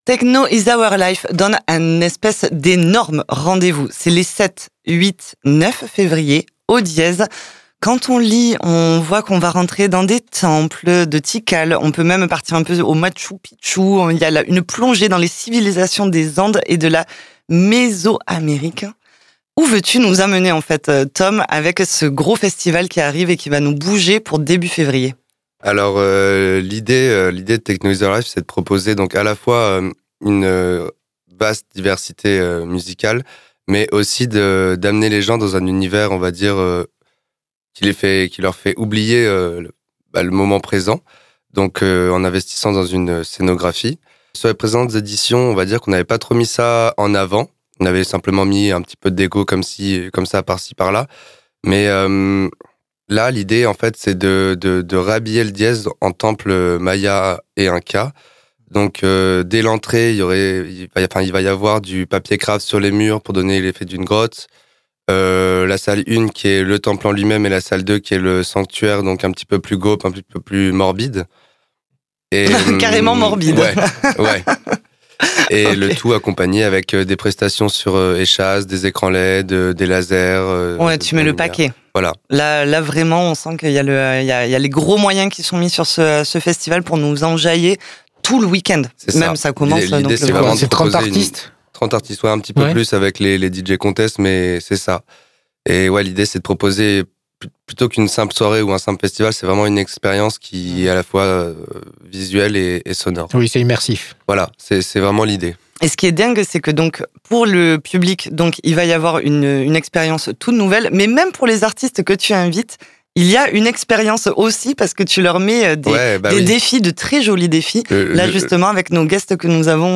Interview de nos guests ! Emission exceptionnelle ce soir avec non pas 1, 2 mais 4 guests !